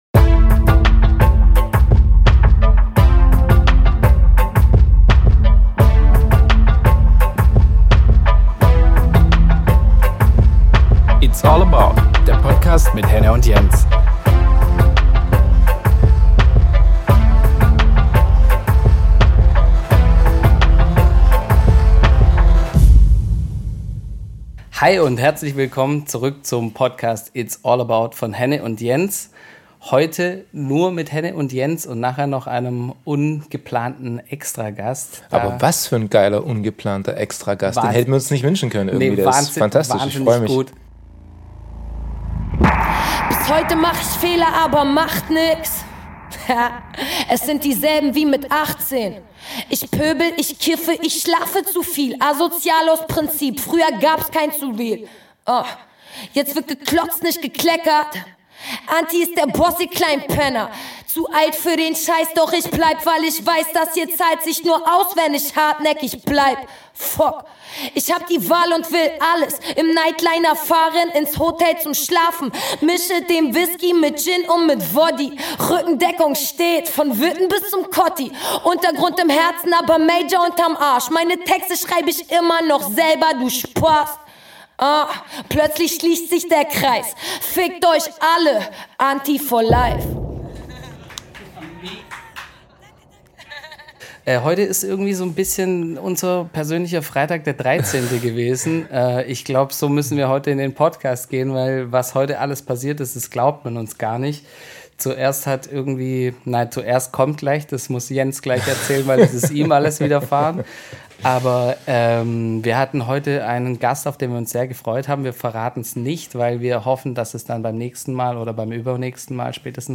'It's all about', im Gespräch mit Antifuchs über The National, Ellen Allien,